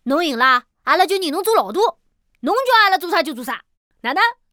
c02_5小男孩_2.wav